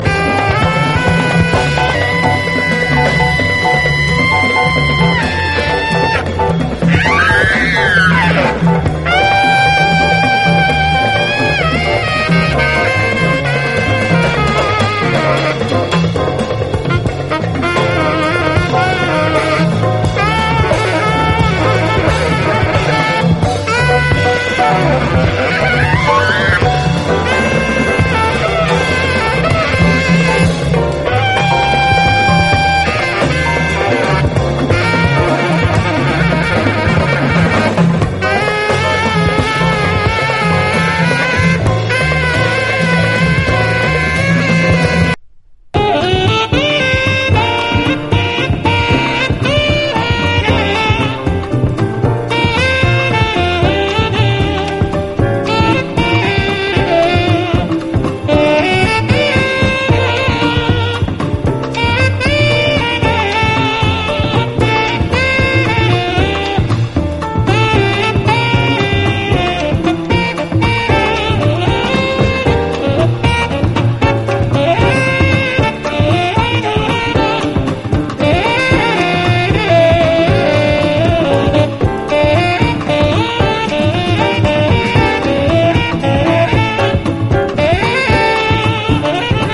JAZZ / OTHER / JAZZ FUNK / DRUM BREAK / SAMPLING SOURCE
ドラム・ブレイク満載！